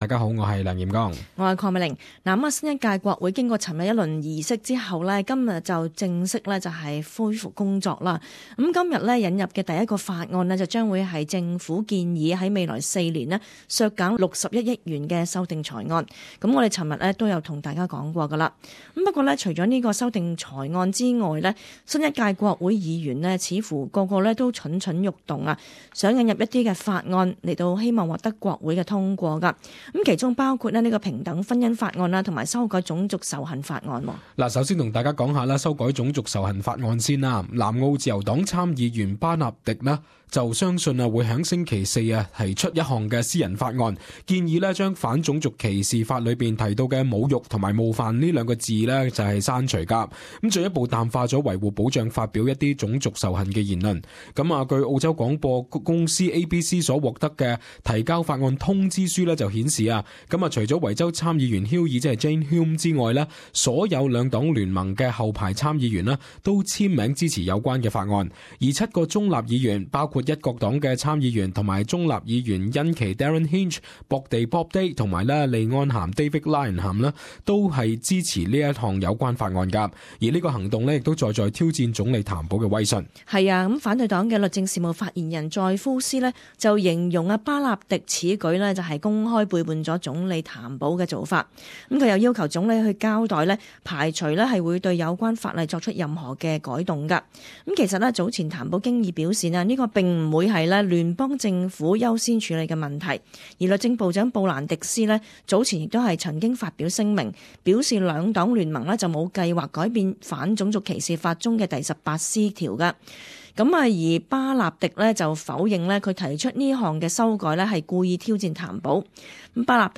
【時事報導】 譚保面對新國會, 挑戰多多